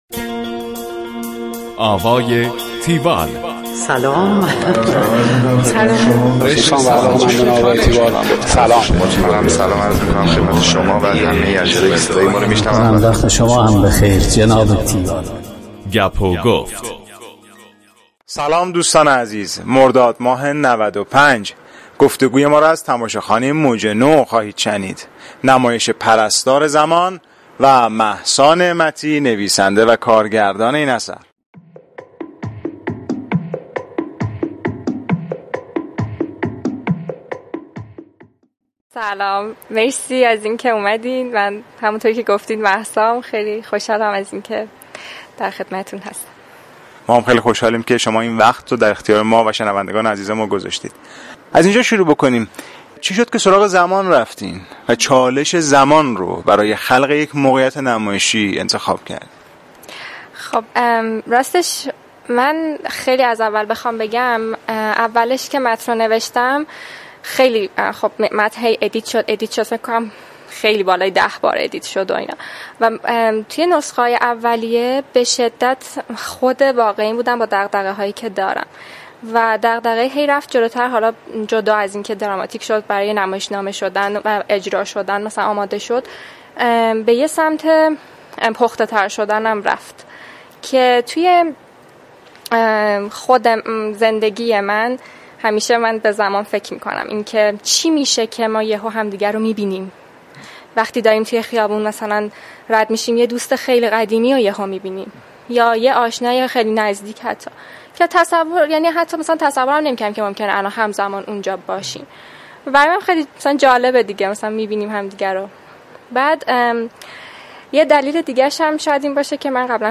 گفتگوی تیوال با